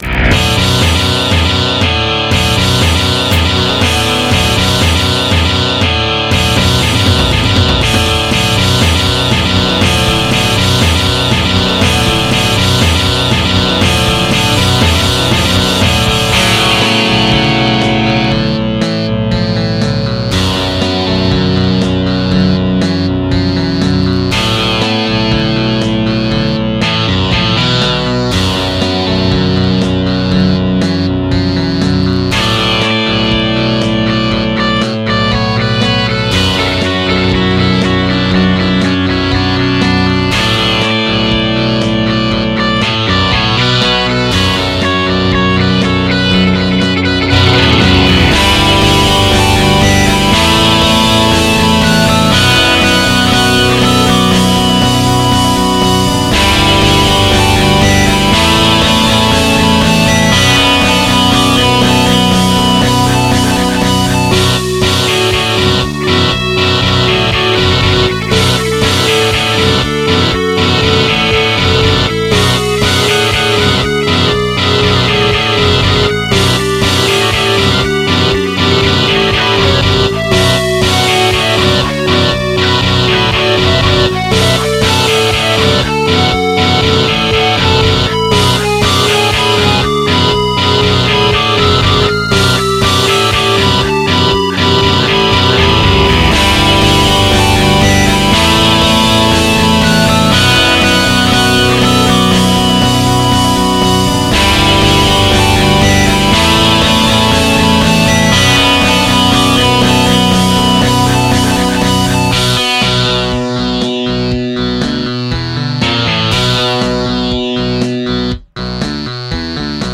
MIDI Cover Remix